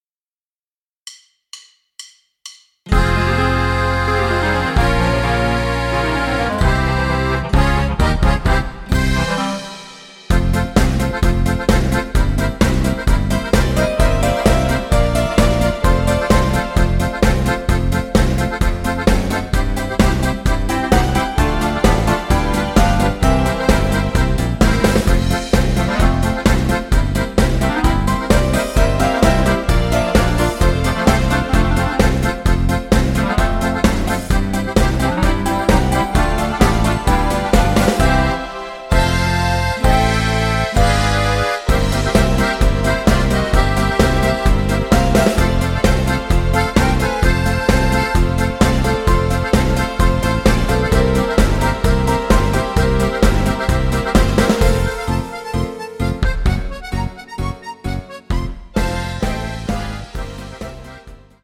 Playback, karaoké, instrumental